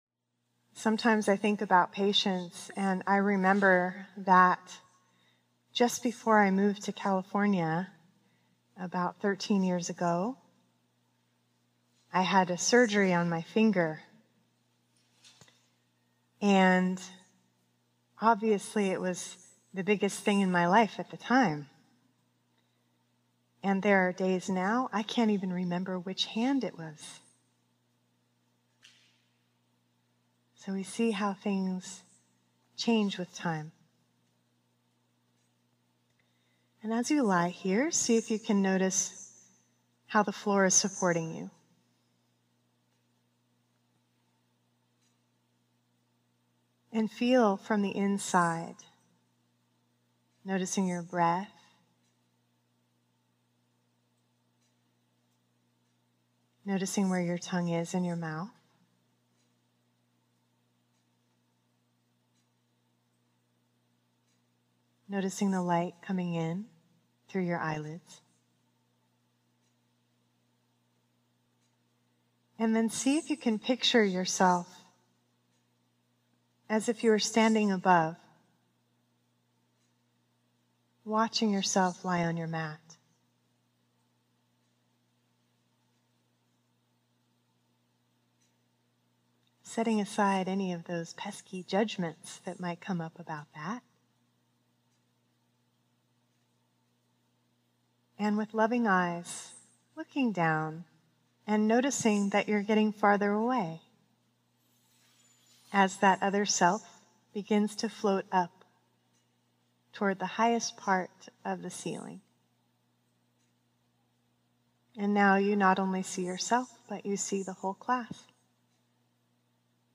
This guided meditation helps give us perspective about life by zooming out from the intensity of life.